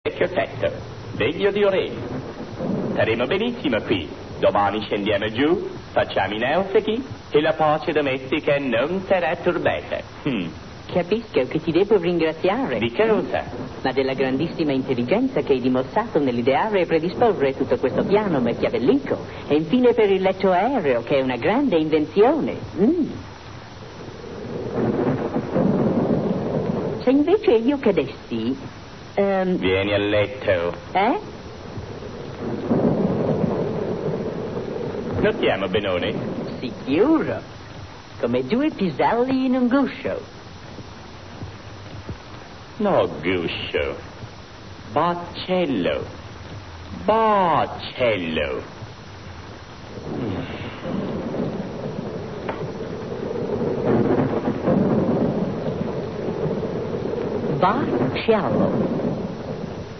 Il mondo dei doppiatori
Sordi però apportò una modifica importante alla voce del personaggio di Ollio trasformandolo in basso, mentre, nella realtà, Oliver Hardy era tenore.